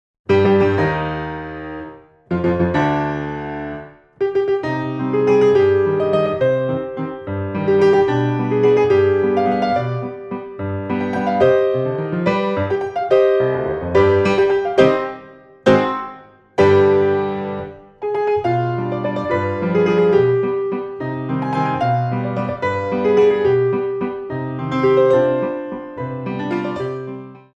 Piano music for the dance studio
Grands battements